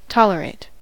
tolerate: Wikimedia Commons US English Pronunciations
En-us-tolerate.WAV